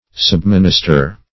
Subminister \Sub*min"is*ter\, v. t. [L. subministrare,